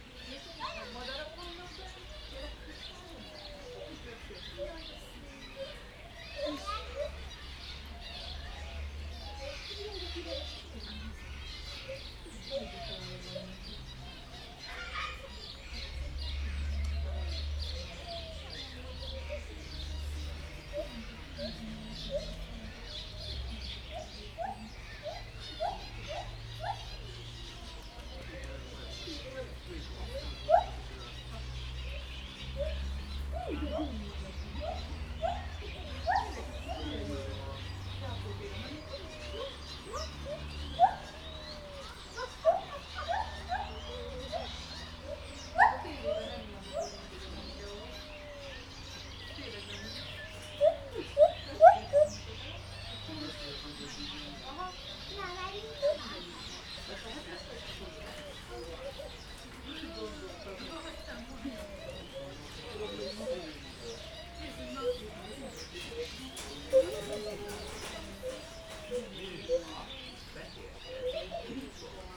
Directory Listing of /_MP3/allathangok/jaszberenyizoo2016_professzionalis/feherkezugibbon/